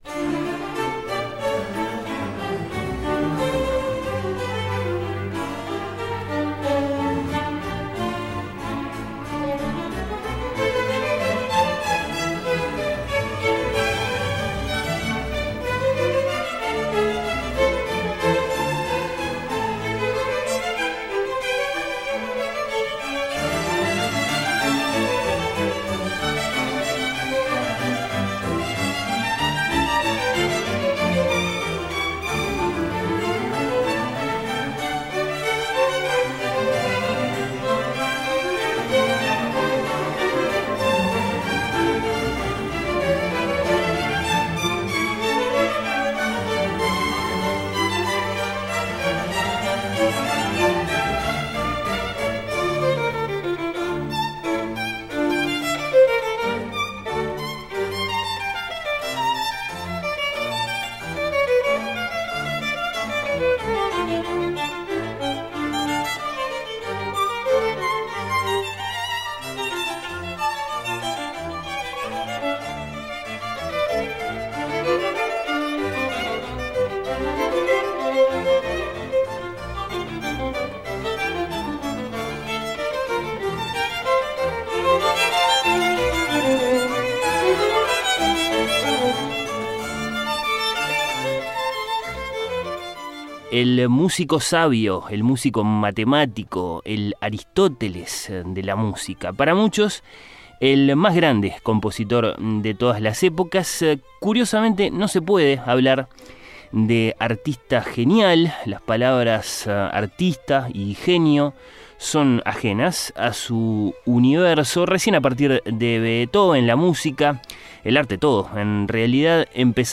Está compuesto para solistas, coro y orquesta barroca, fue la primera obra religiosa mayor de Bach sobre un texto latino y es hoy una de sus obras vocales más interpretadas.